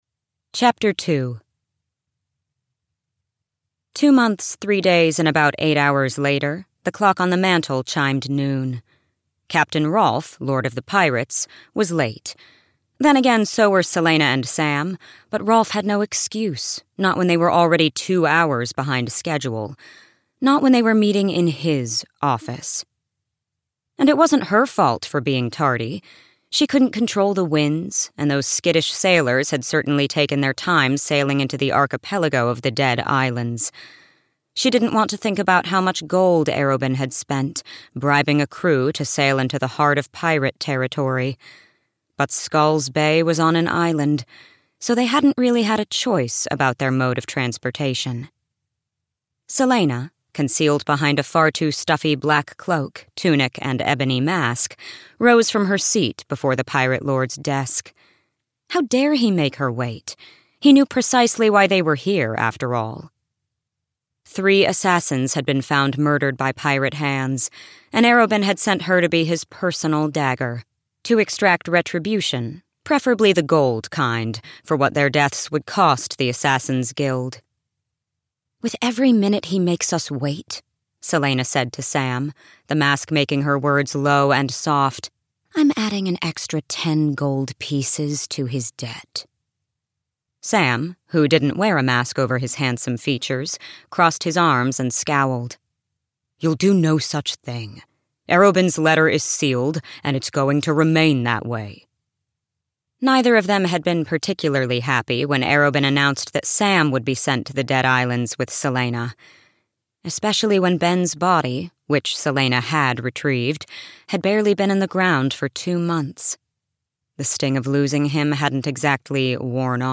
The Assassin's Blade (EN) audiokniha
Ukázka z knihy